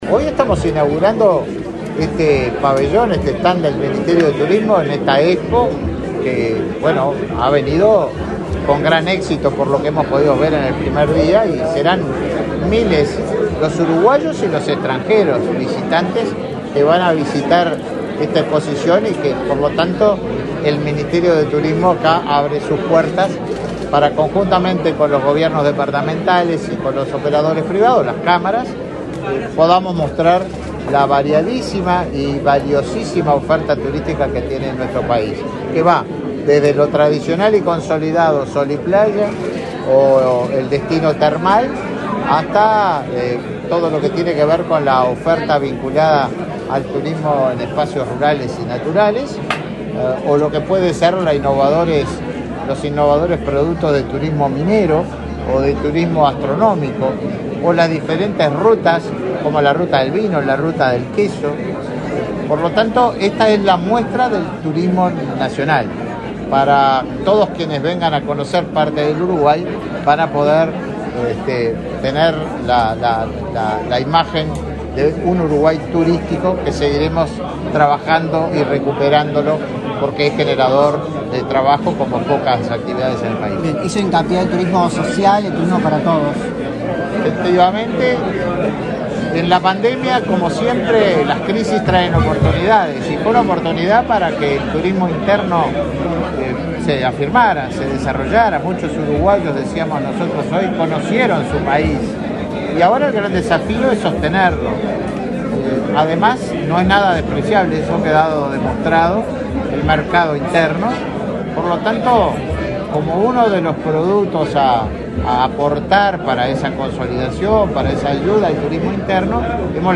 Declaraciones a la prensa del ministro de Turismo, Tabaré Viera, en inauguración de stand en la Expo Prado 2022
Viera prensa.mp3